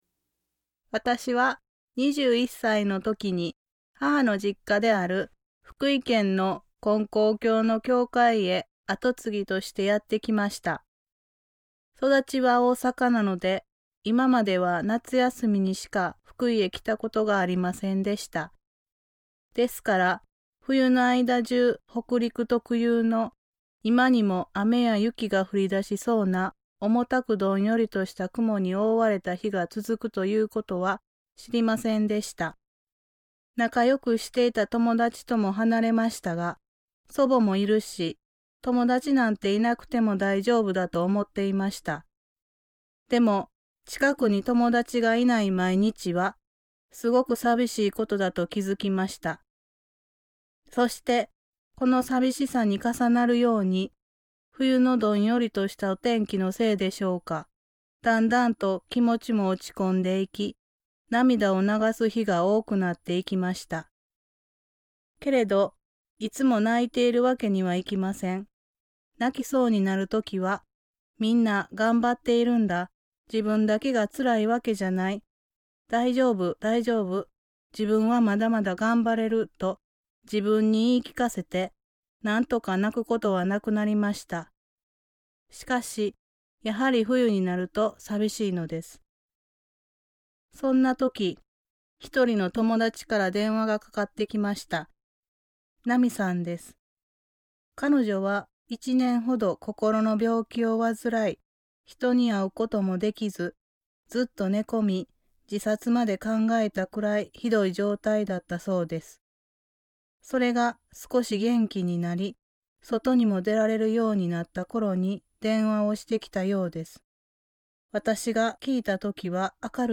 ●先生のおはなし